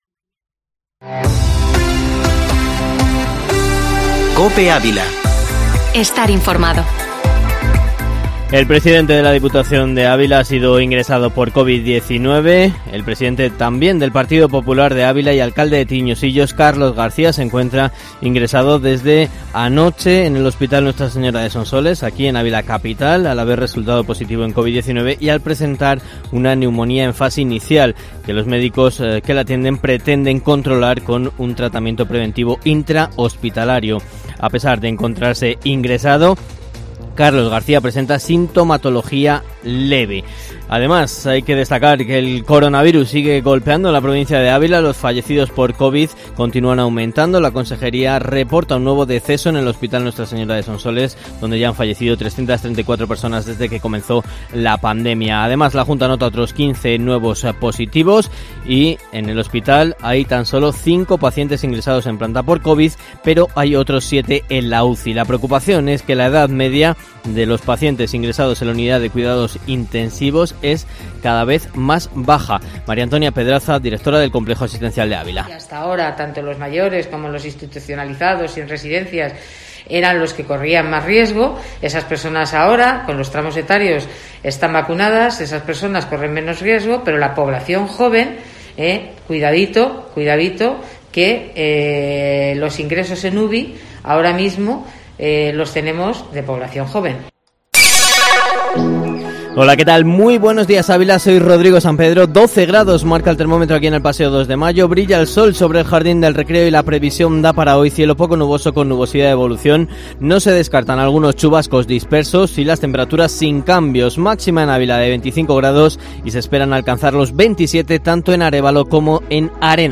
Informativo Matinal Herrera en COPE Ávila, informativo local y provincial